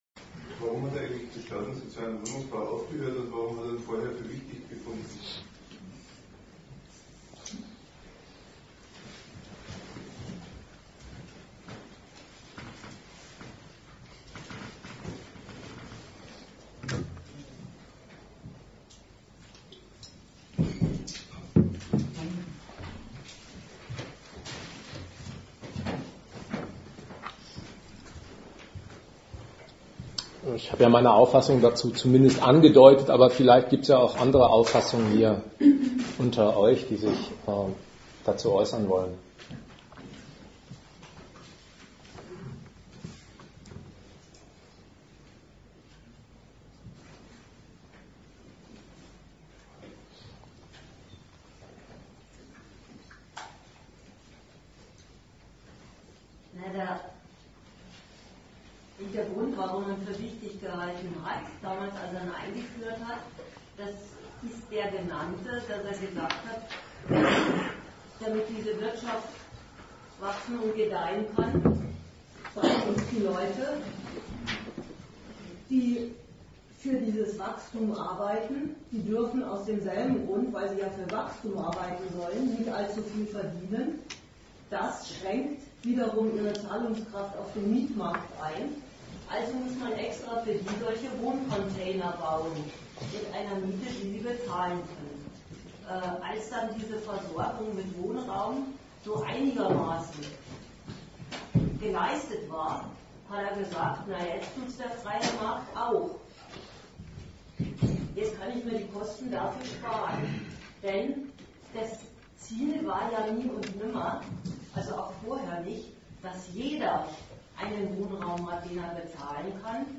Datum 20.02.2013 Ort Regensburg Themenbereich Arbeit, Kapital und Sozialstaat Veranstalter Forum Kritik Regensburg Dozent Gastreferenten der Zeitschrift GegenStandpunkt In sogenannten Ballungsgebieten steigen die Mietpreise unaufhörlich.